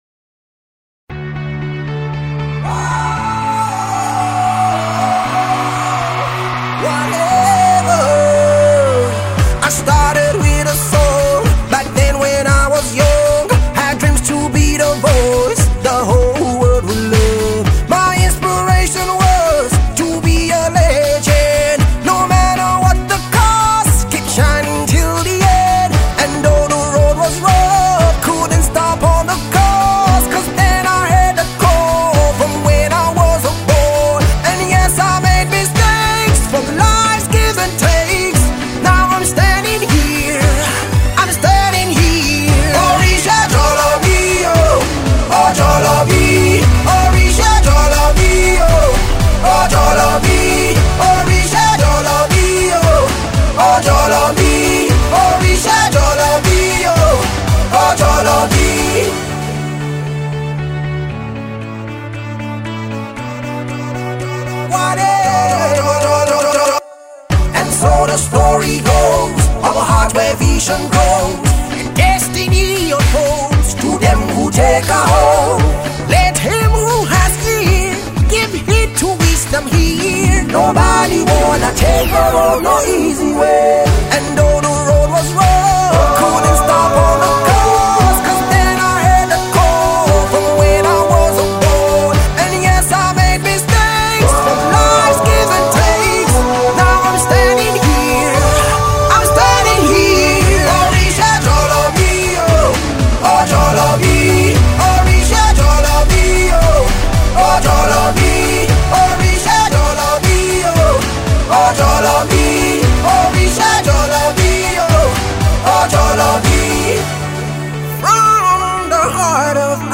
Gospel-Inspirational